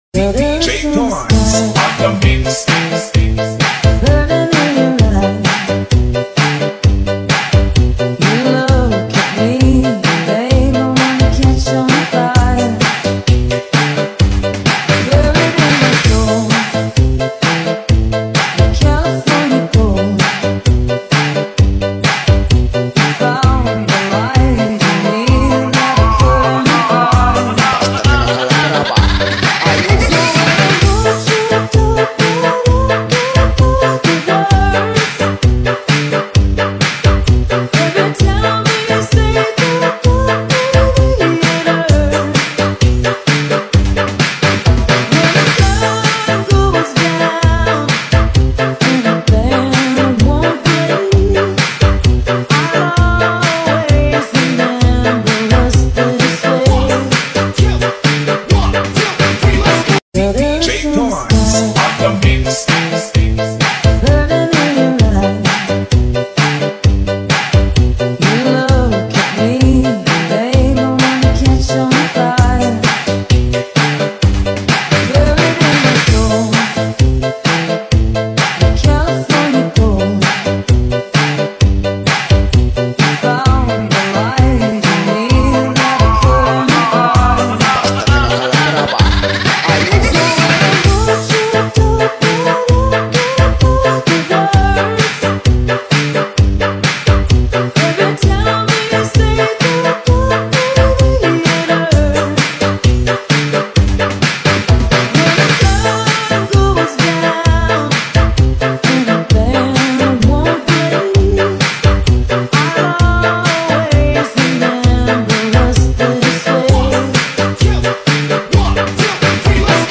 disco_no_stop_320kbps.mp3